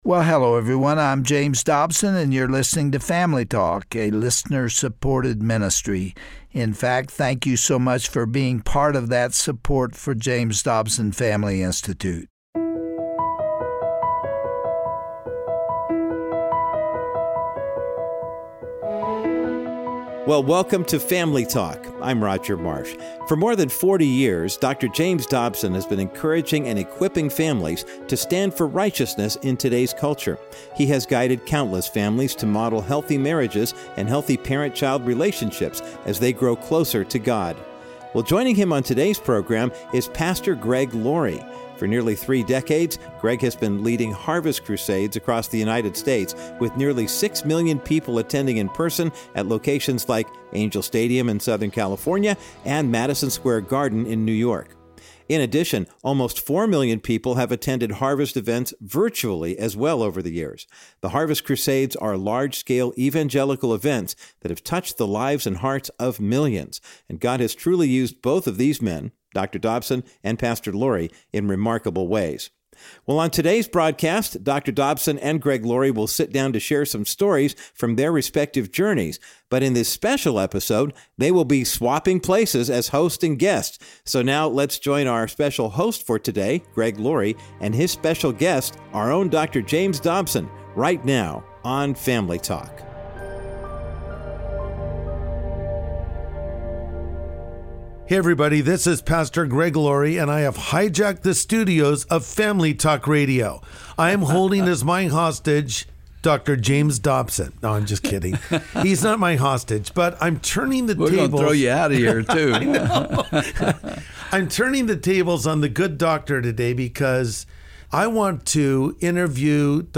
Turning the Tables: Greg Laurie Interviews Dr. James Dobson
On this edition of Family Talk, evangelist Greg Laurie turns the tables and interviews America's beloved Christian psychologist. Tune in to hear more about the challenges Dr. Dobson overcame in life, and how he became successful by depending solely on God.